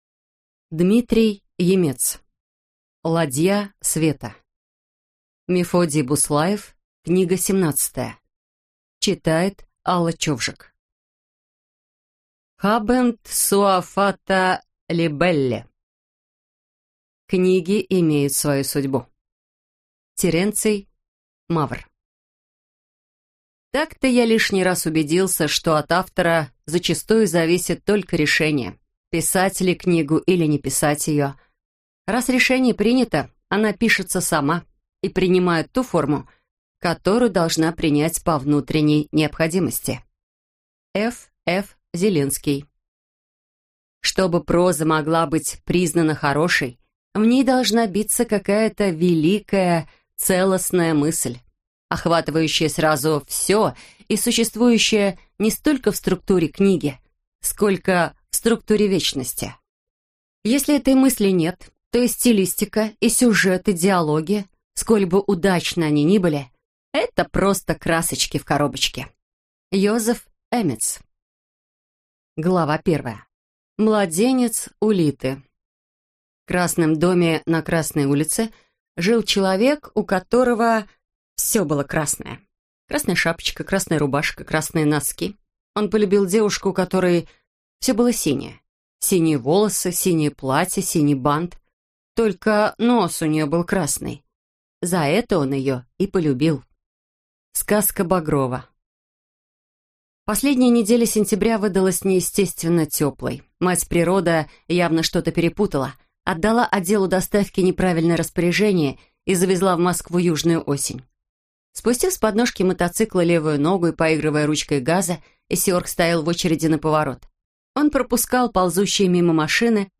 Аудиокнига Ладья света | Библиотека аудиокниг